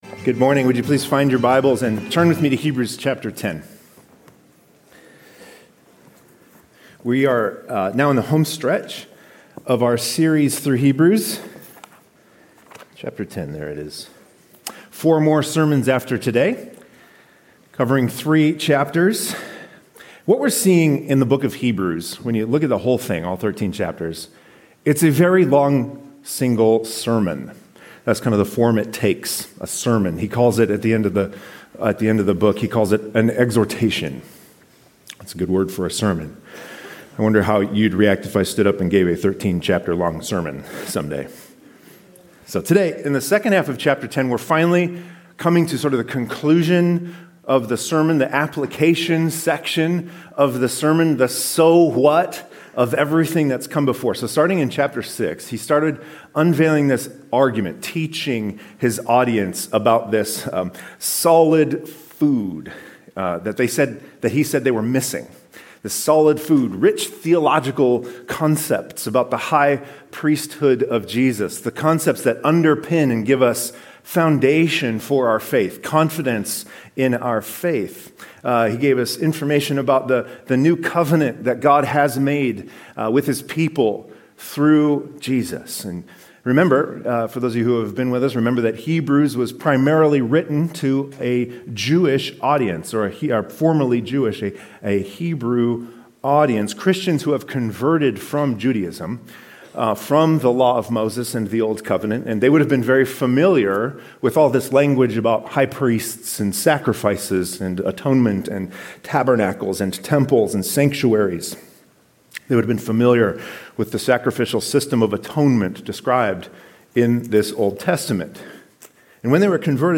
Four more sermons left after today covering three more chapters.